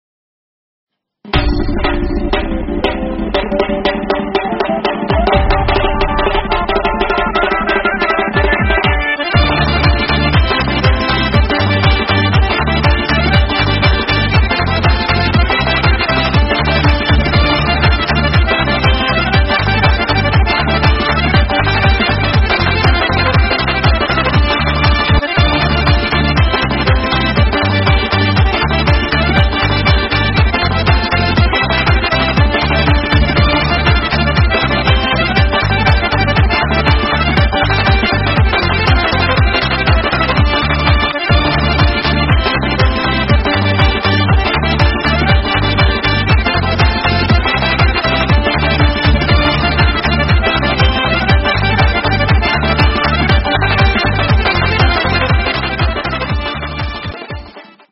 Лезгинка музыка без слов